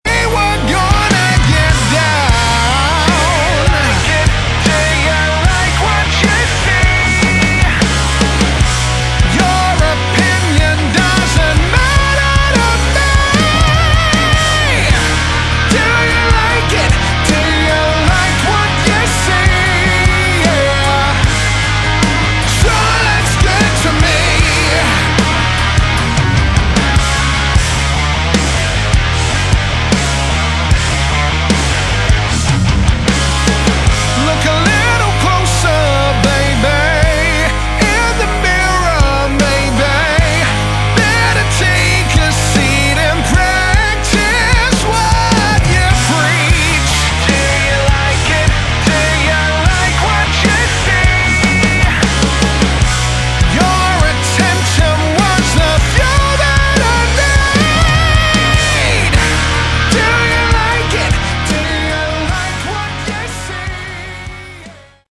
Category: Hard Rock
Lead Vocals
Guitar
Bass Guitar
Drums, Percussion
keys, piano